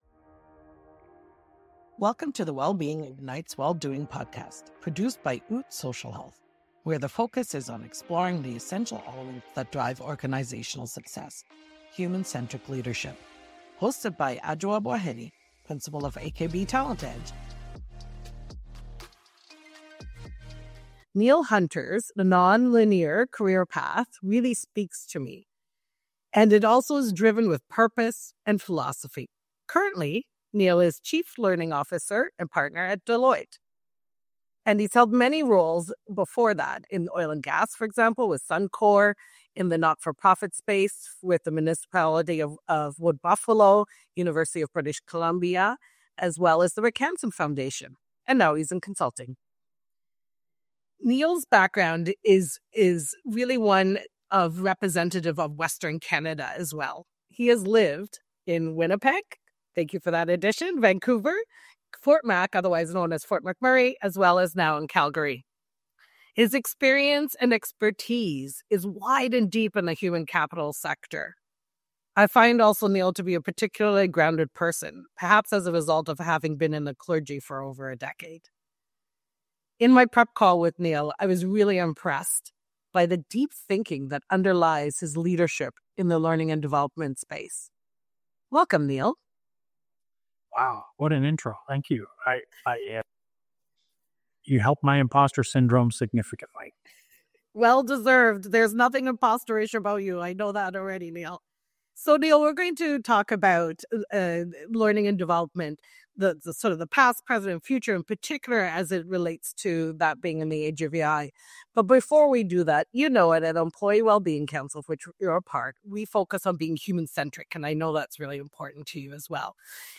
guest host
interviews